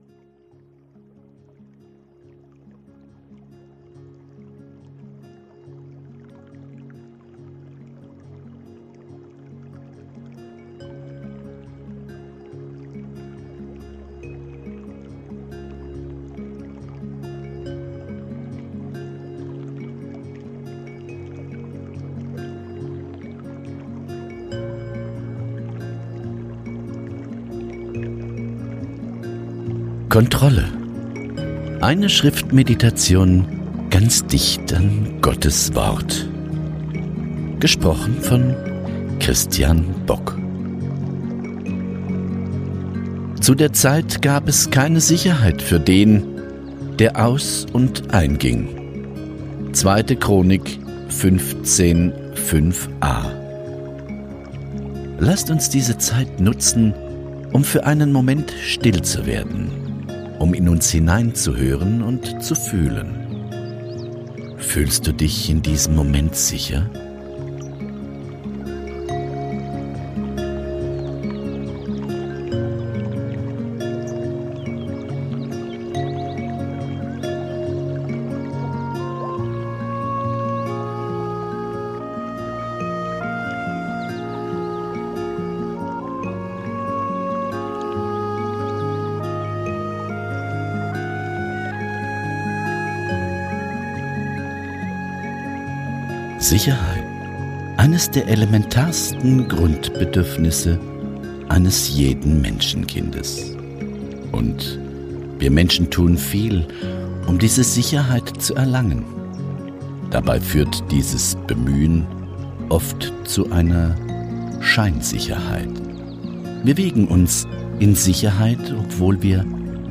Eine Schriftmeditation ganz dicht an GOTTES Wort